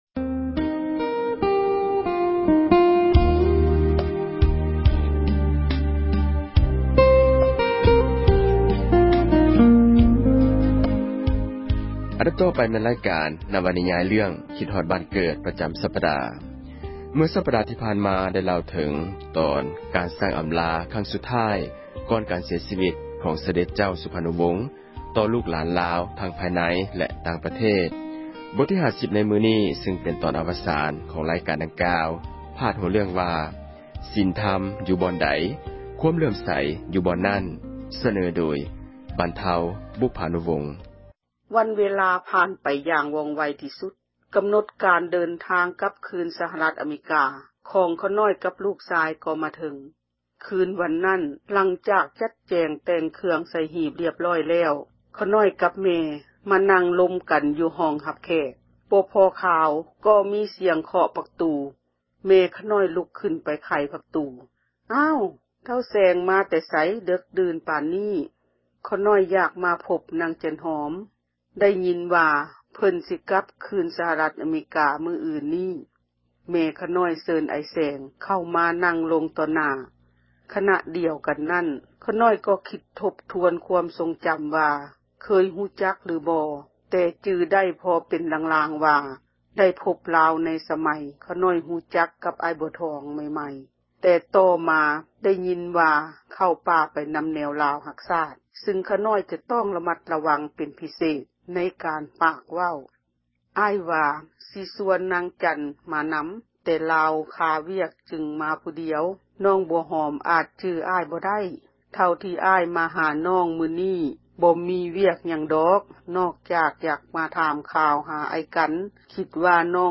ນິຍາຍ ເຣື້ອງ ຄິດຮອດບ້ານເກີດ ປະຈຳສັປດາ ບົດ ອາວະສານ.